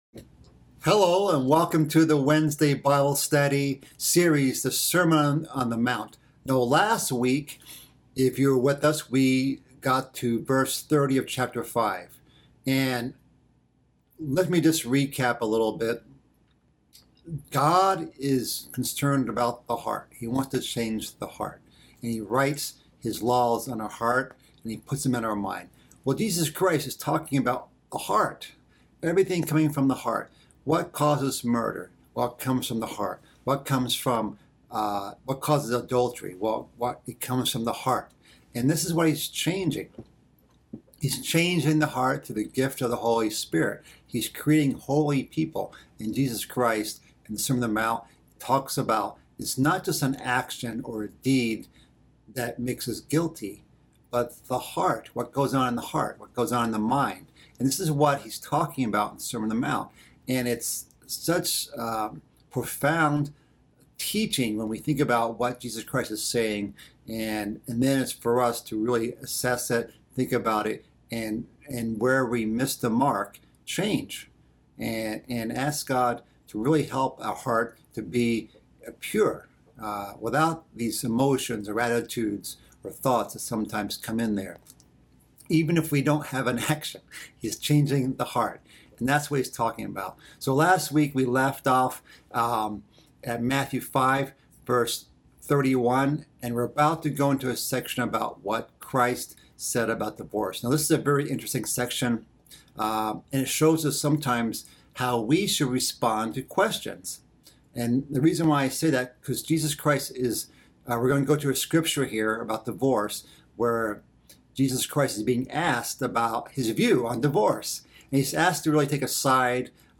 This is part of a mid-week Bible study series covering the sermon on the mount. This week's study covers the section on divorce, oaths, and how to deal with disputes in strained relationships.